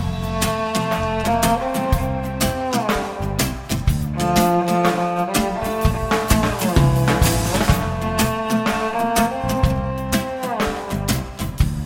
描述：这让我想到了一个漂亮的慢走去见某人。
标签： 器乐 电子 贝斯 吉他 轻松的节奏 鼓声
声道立体声